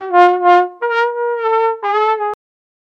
sad-trombone.mp3